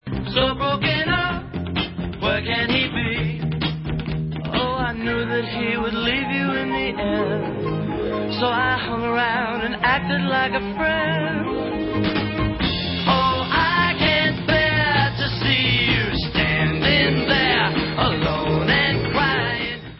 sledovat novinky v oddělení Pop/Oldies